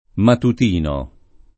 matutino [ matut & no ]